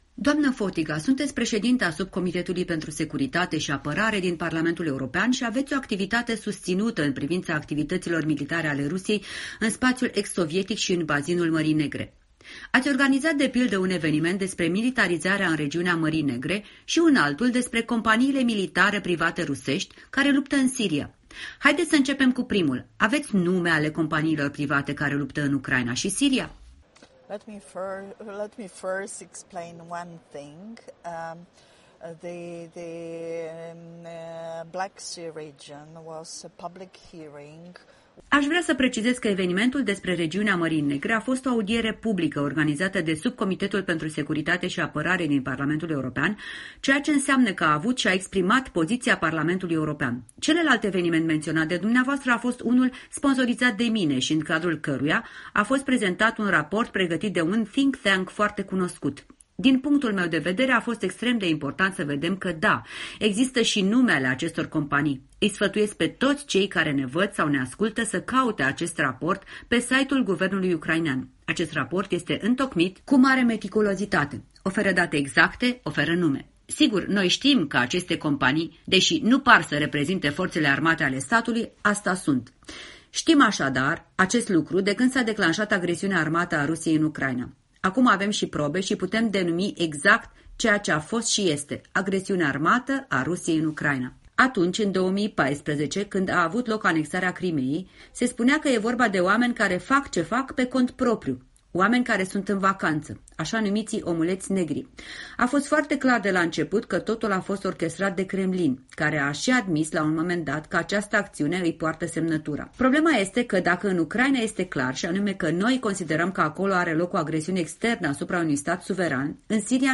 Un interviu cu președinta Subcomisiei de Securitate și Apărare a Parlamentului European despre politica de expansiune militară a Rusiei.
Un interviu cu europarlamentar conservatoare Ana Elzbieta Fotyga